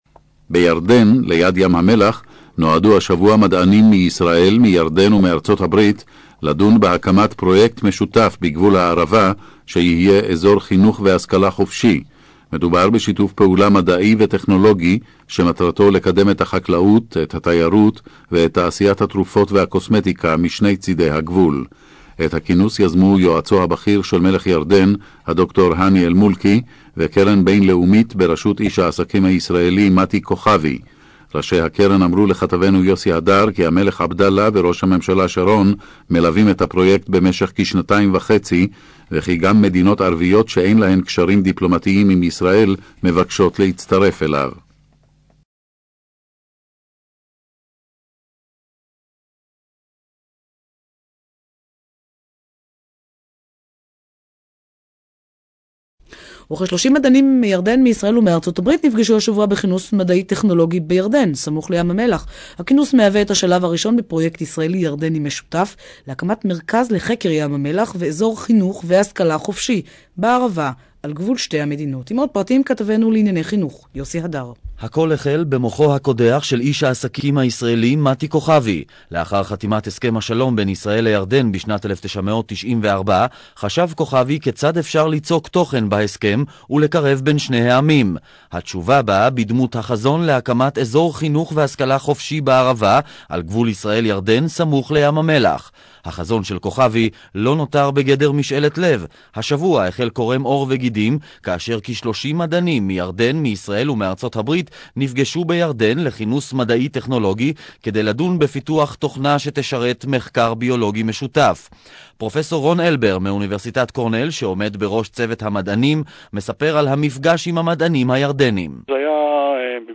Israeli Radio Report on the BTR Project (audio, in Hebrew)
BTR_IsraelRadioReport.mp3